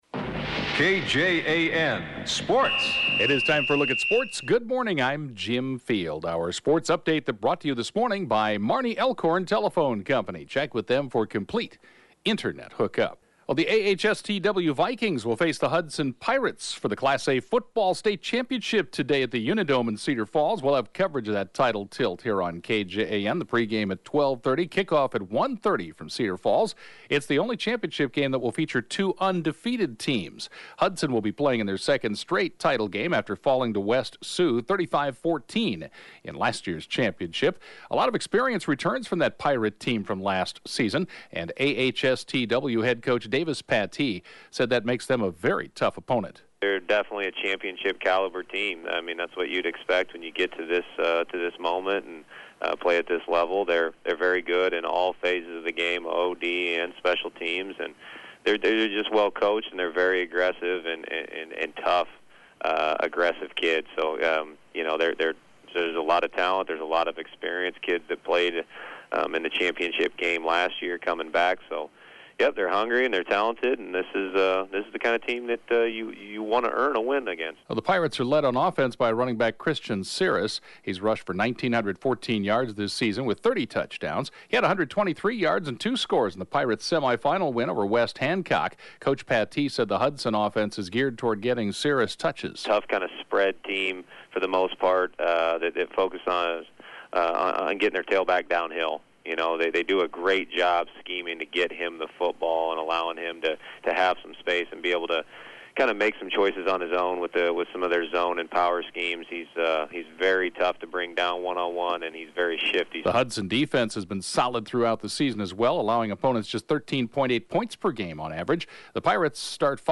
(Podcast) KJAN Morning Sports report, 11/15/18